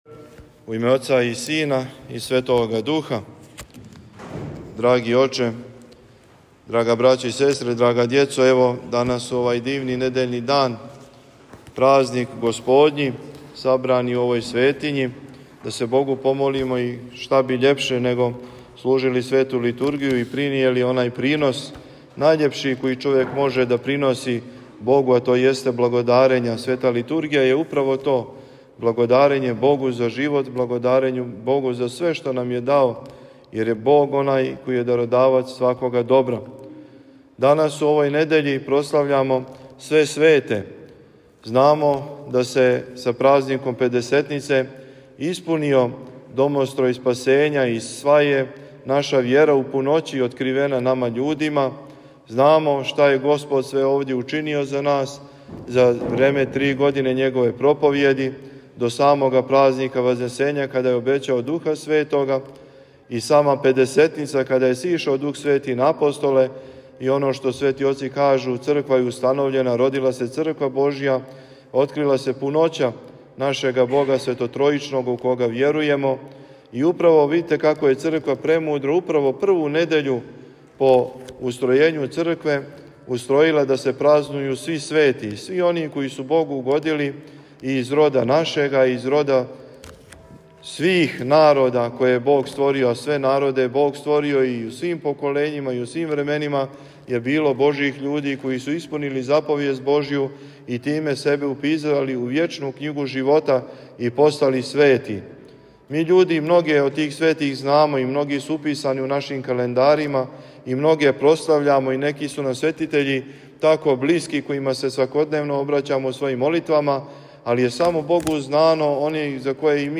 Беседу можете послушати у целости.